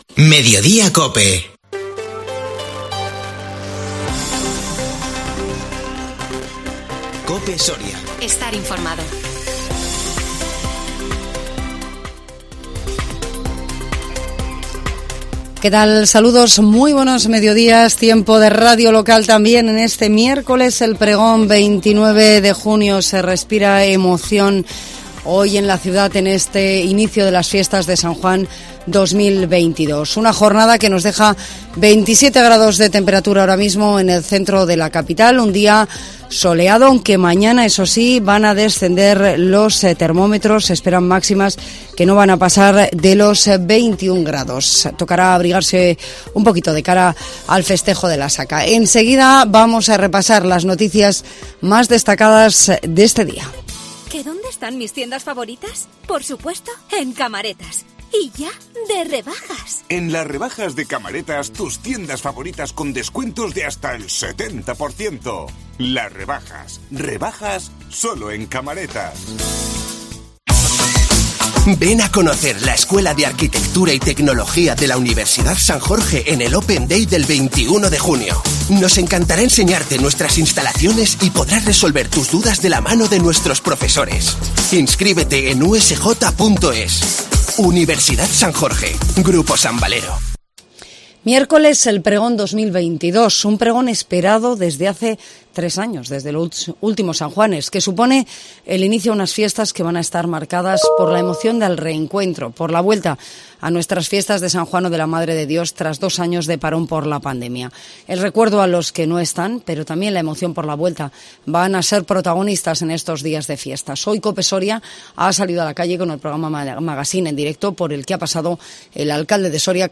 INFORMATIVO MEDIODÍA COPE SORIA 29 JUNIO 2022